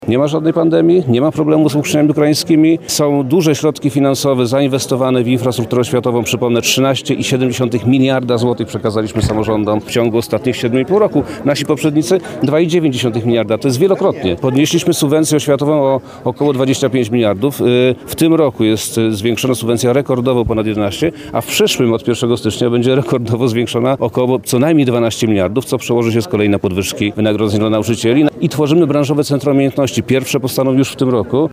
– To będzie pierwszy od lat tak spokojny początek roku szkolnego – mówi minister edukacji i nauki Przemysław Czarnek.